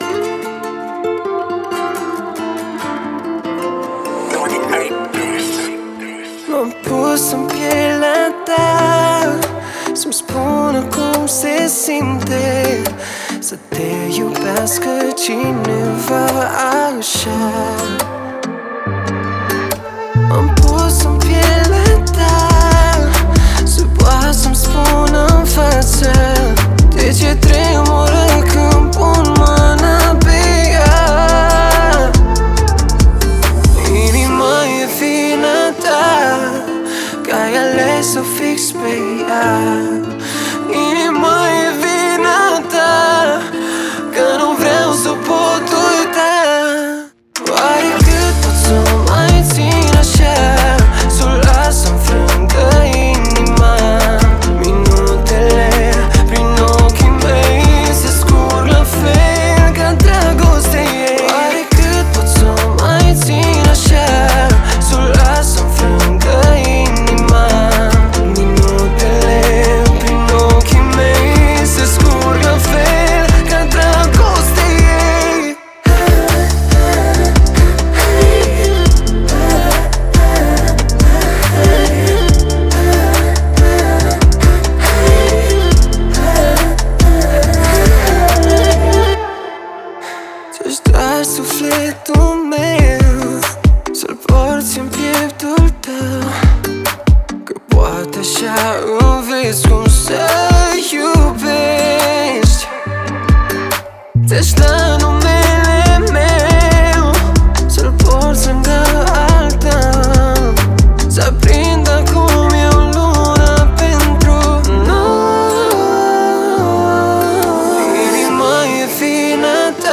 это зажигательная композиция в жанре поп с элементами фолка